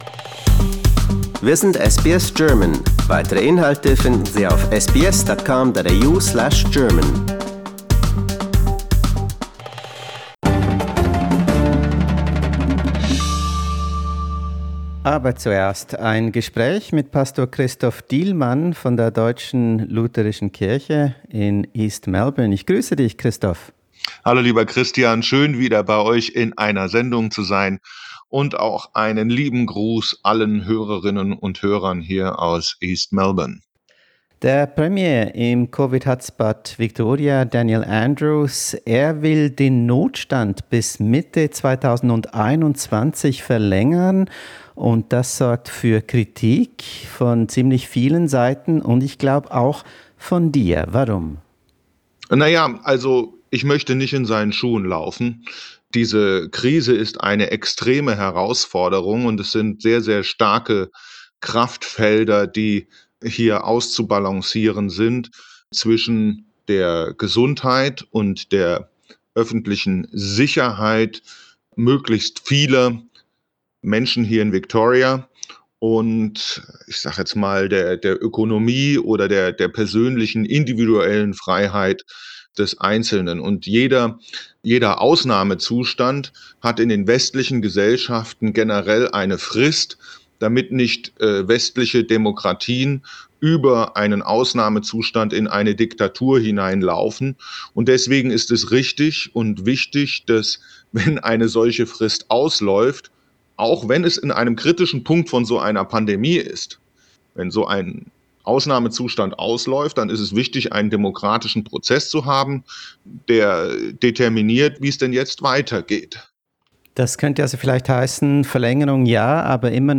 Video-Interview